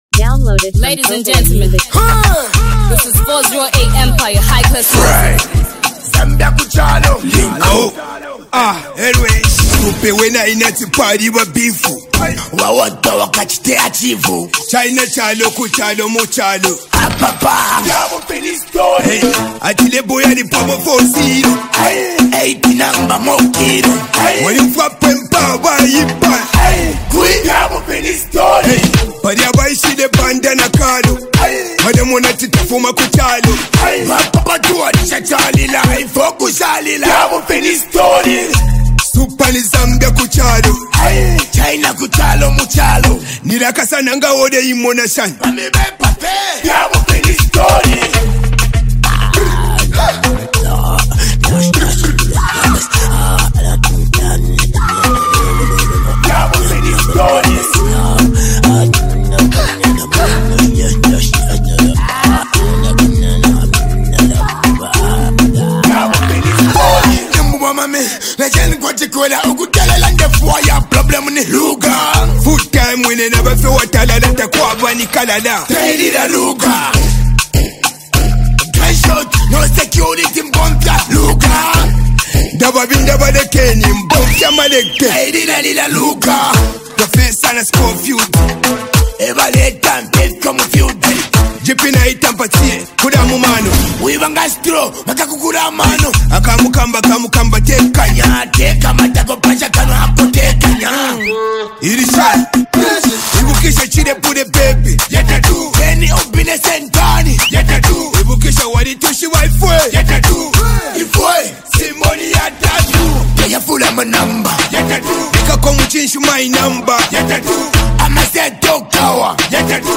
street anthem
Through confident verses and a catchy hook